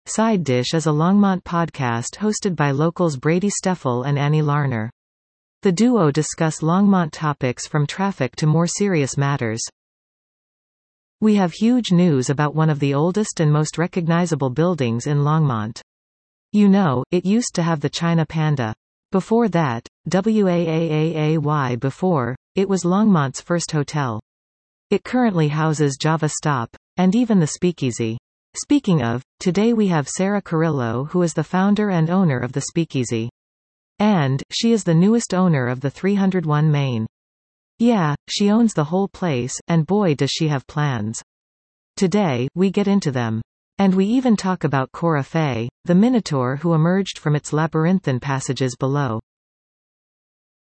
The duo discuss Longmont topics from traffic to more serious matters.